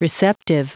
Prononciation du mot receptive en anglais (fichier audio)
Prononciation du mot : receptive